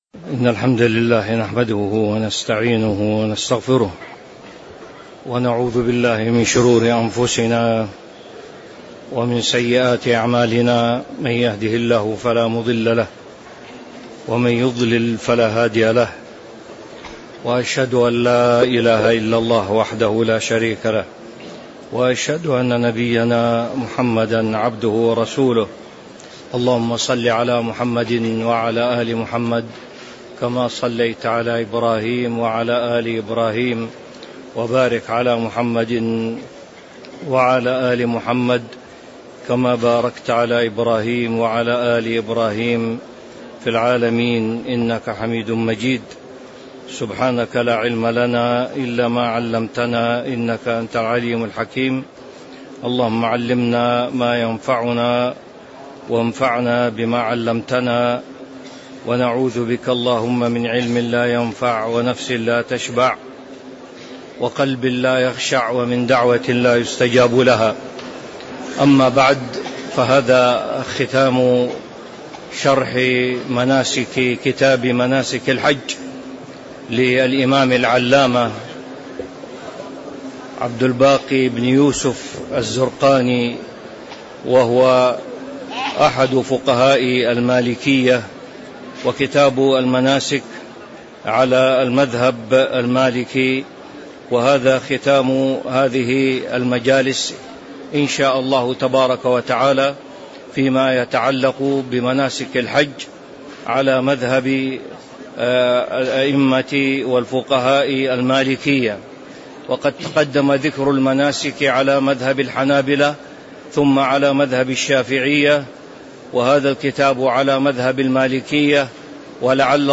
تاريخ النشر ٢٨ ذو الحجة ١٤٤٦ هـ المكان: المسجد النبوي الشيخ